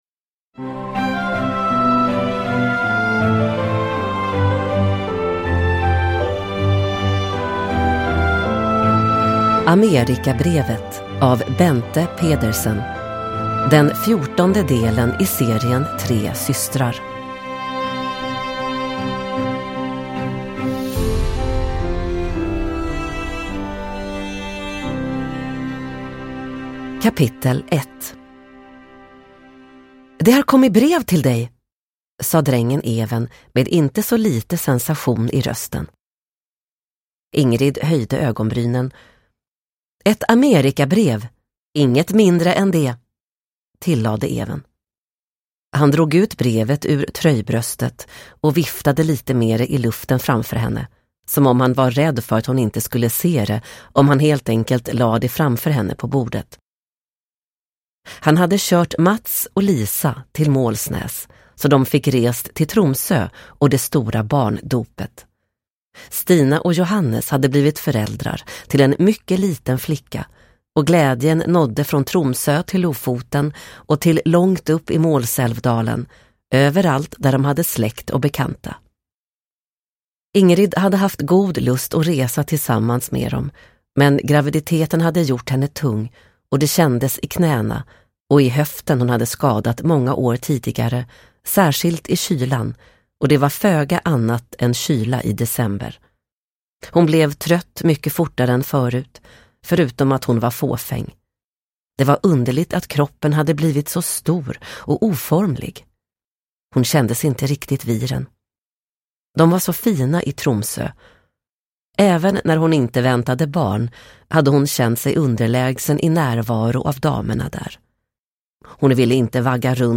Amerikabrevet – Ljudbok – Laddas ner